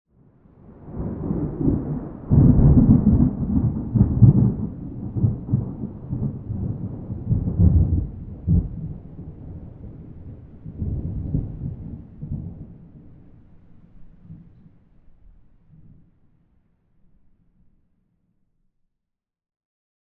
thunderfar_15.ogg